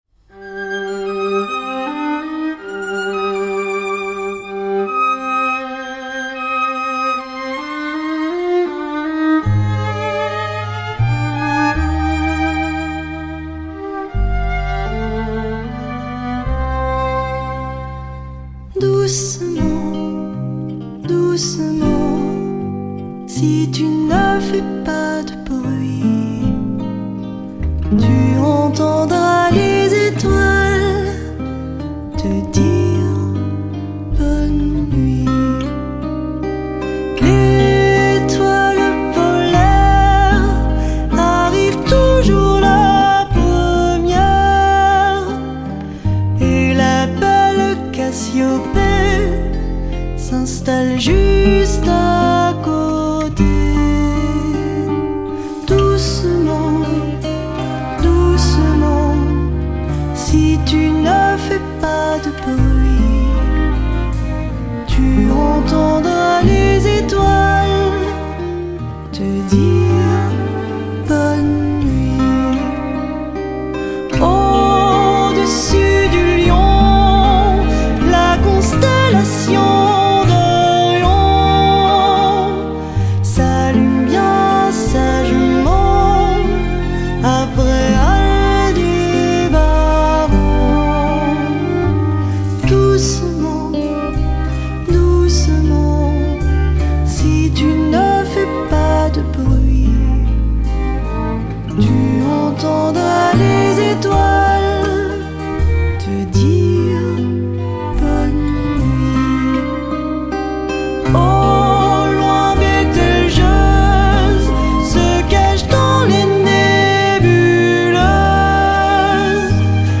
Колыбельная на французском 🇫🇷